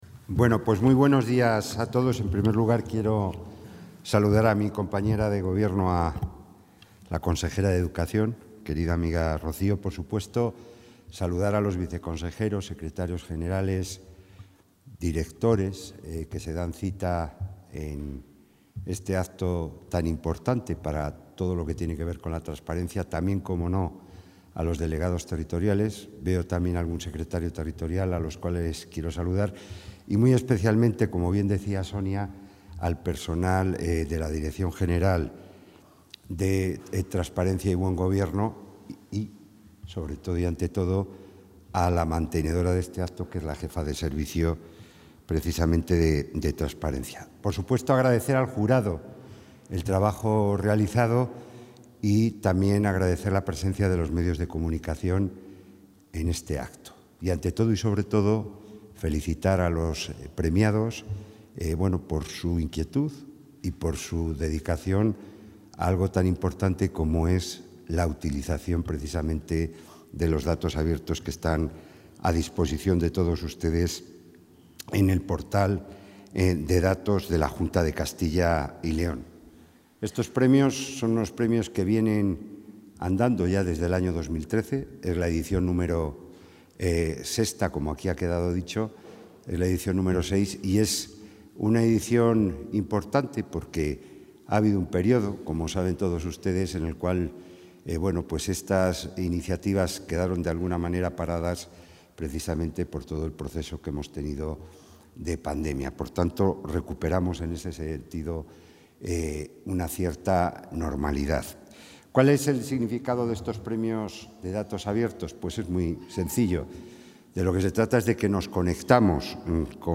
Intervención del consejero de la Presidencia.
El consejero de la Presidencia, Jesús Julio Carnero, ha participado hoy, en Valladolid, en la entrega de los premios del VI Concurso Datos Abiertos de la Comunidad de Castilla y León, en la que también ha estado presente la consejera de Educación, Rocío Lucas, y donde se ha reconocido la valía de los trabajos presentados utilizando conjuntos de datos del Portal de Datos Abiertos de la Junta de Castilla y León, listos para poder ser utilizados por los ciudadanos.